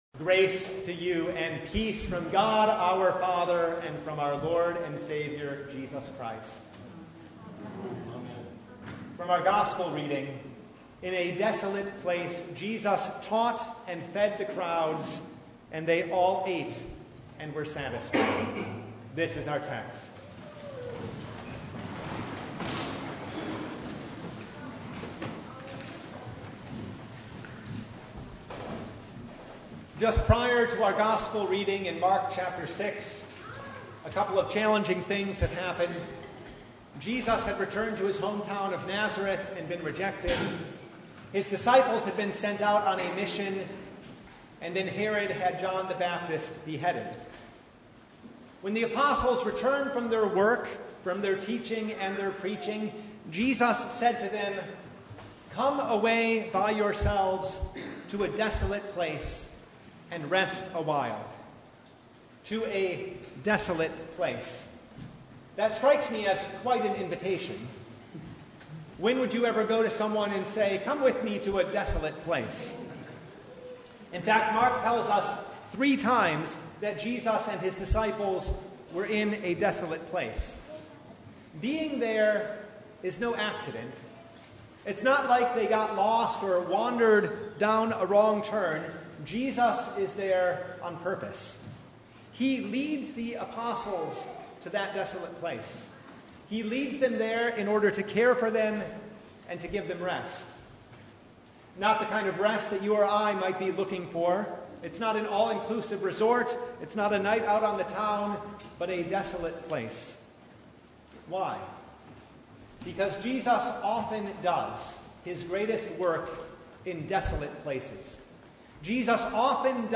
Mark 6:30-44 Service Type: Sunday Even in a desolate place